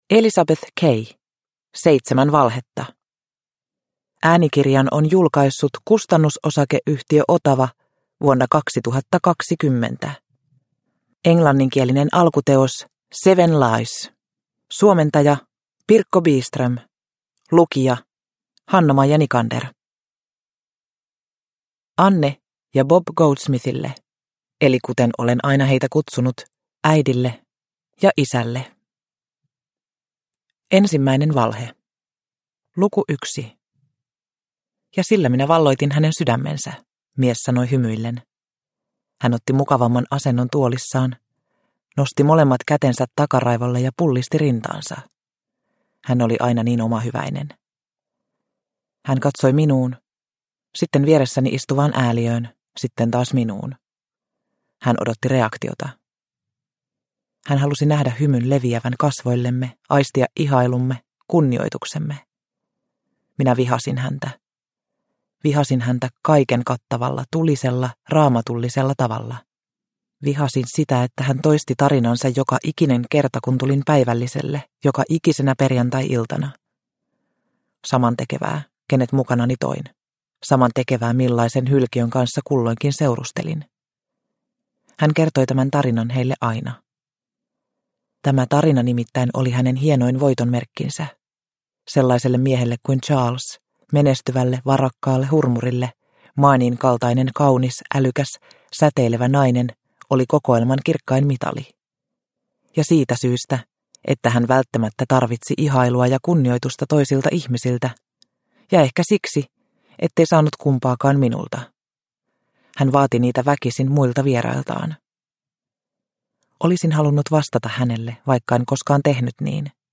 Seitsemän valhetta – Ljudbok – Laddas ner